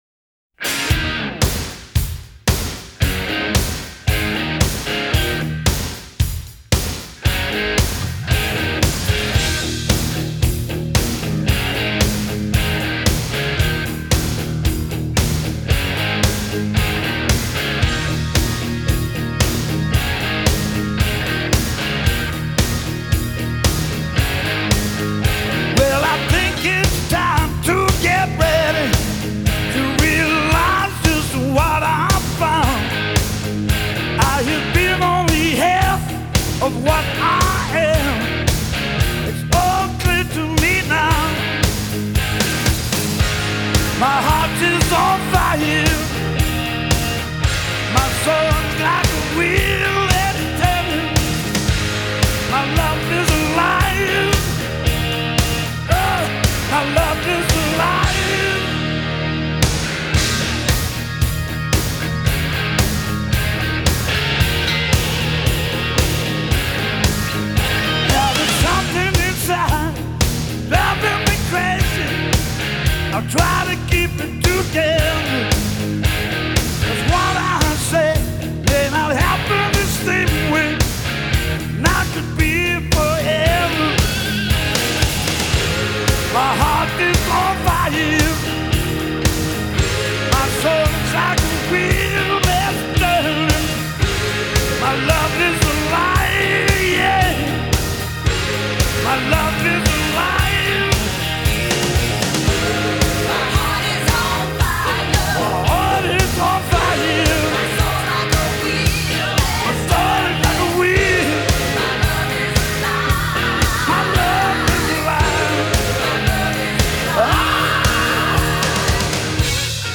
характерным хриплым вокалом